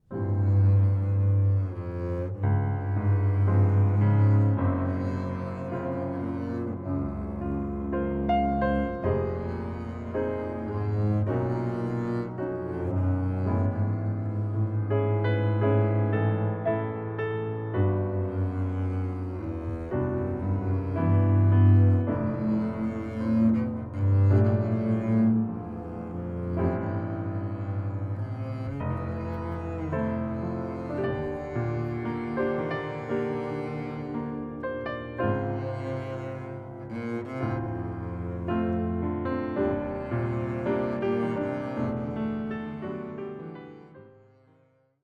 Recorded on July.26th 2022 at Studio Happiness Engineer
vln